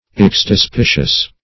Search Result for " extispicious" : The Collaborative International Dictionary of English v.0.48: Extispicious \Ex`ti*spi"cious\, a. [L. extispicium an inspection of the inwards for divination; extra the entrails + specer to look at.] Relating to the inspection of entrails for prognostication.